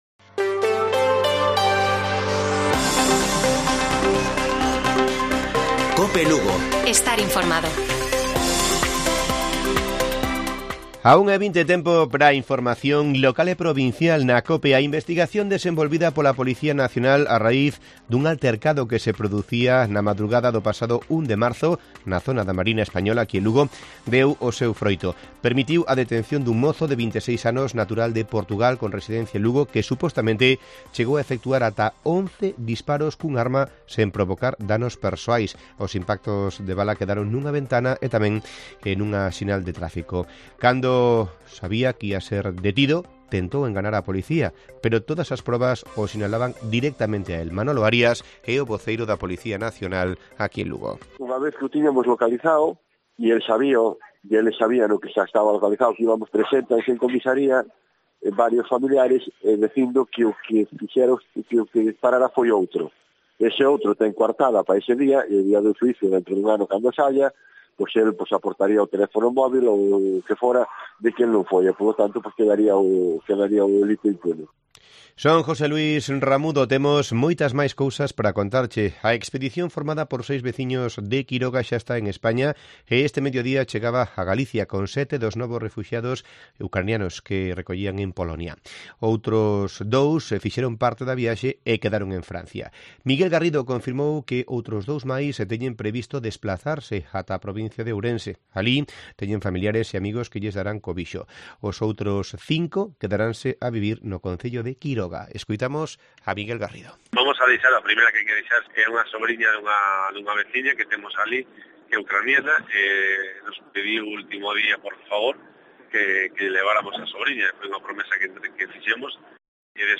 Informativo Mediodía de Cope Lugo. 11 de marzo. 13:50 horas